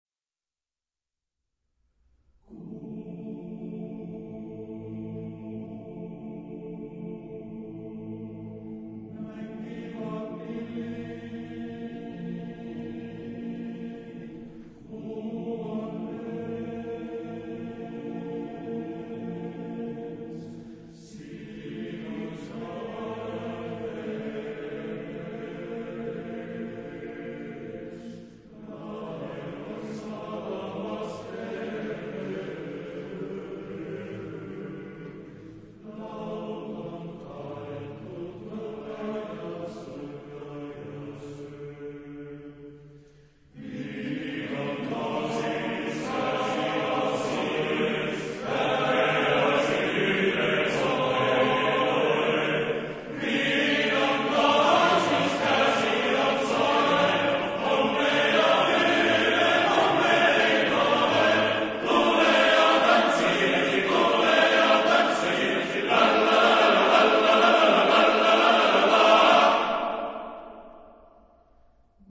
Type de choeur : TTBB  (4 voix égales d'hommes )